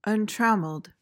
PRONUNCIATION:
(uhn-TRAM-uhld)